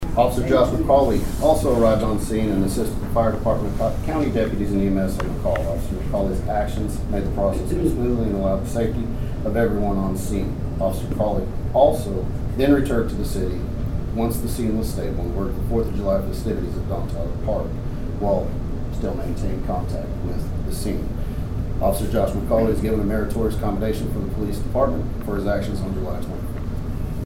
The Dewey City Council honored two police officers during the city council meeting at Dewey City Hall on Monday night.